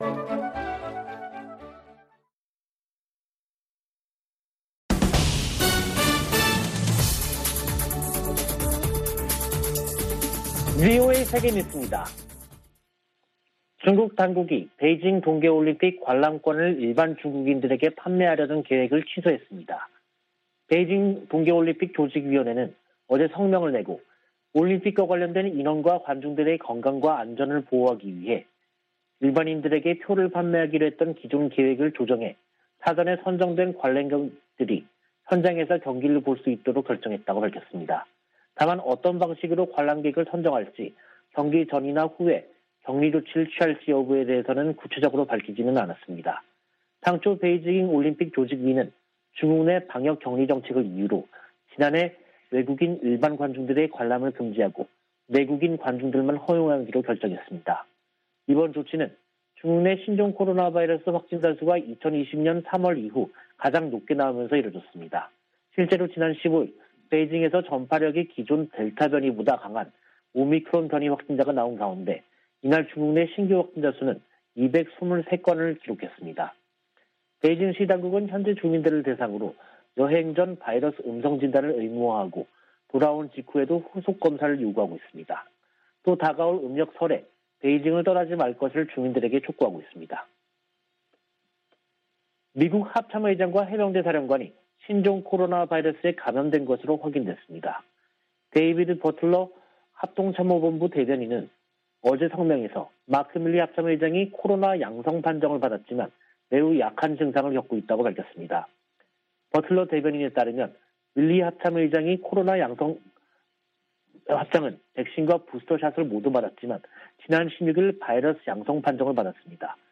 VOA 한국어 간판 뉴스 프로그램 '뉴스 투데이', 2022년 1월 18일 3부 방송입니다. 북한이 17일 쏜 발사체는 ‘북한판 에이태킴스’인 것으로 파악됐습니다. 성 김 미 국무부 대북특별대표는 북한에 불법 활동을 중단하고 대화에 나서라고 촉구했습니다. 유엔은 북한의 올해 네 번째 미사일 발사에 우려를 표하고, 관련국들이 기존 대화 장치를 활용해 문제 해결에 나설 것을 촉구했습니다.